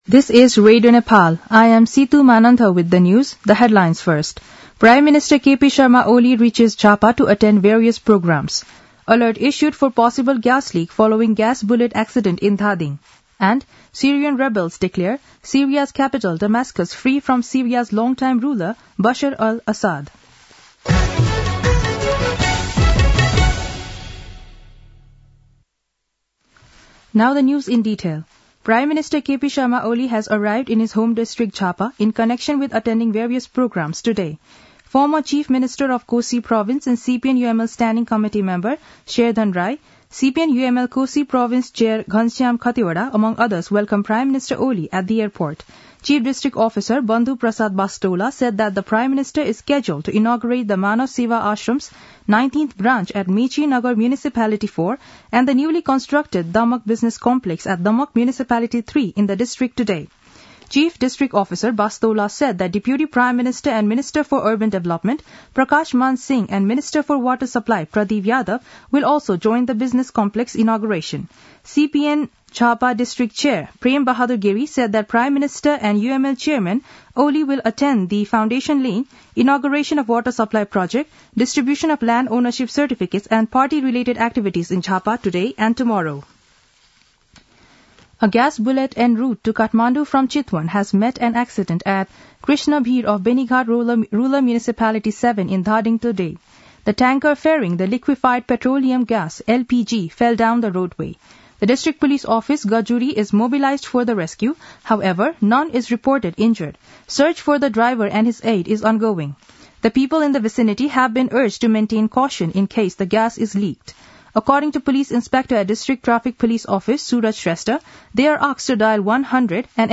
दिउँसो २ बजेको अङ्ग्रेजी समाचार : २४ मंसिर , २०८१
2-pm-english-news-1-6.mp3